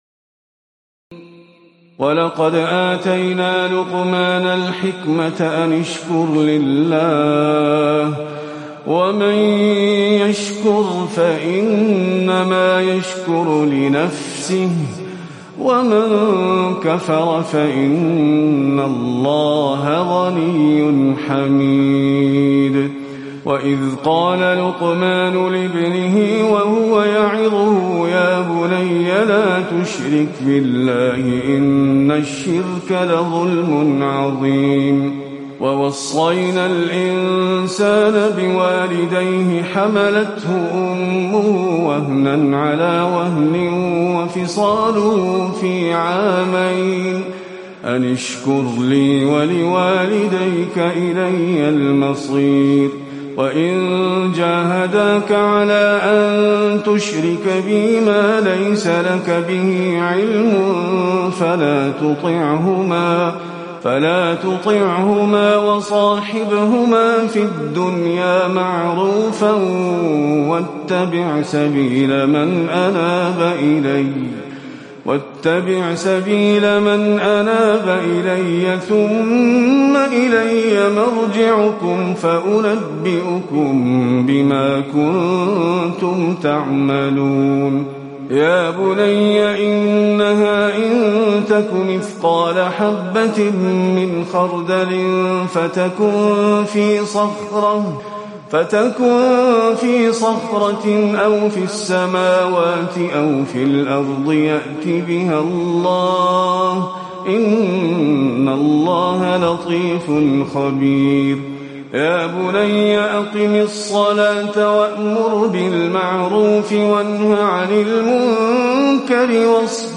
تراويح الليلة العشرون رمضان 1438هـ من سور لقمان (12-34) والسجدة و الأحزاب (1-34) Taraweeh 20 st night Ramadan 1438H from Surah Luqman and As-Sajda and Al-Ahzaab > تراويح الحرم النبوي عام 1438 🕌 > التراويح - تلاوات الحرمين